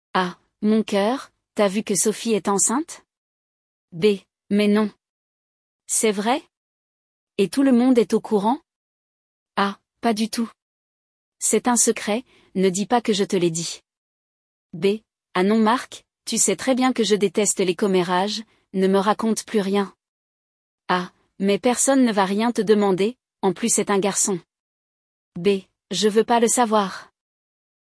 Hoje, vamos ouvir uma conversa sobre a vida dos outros.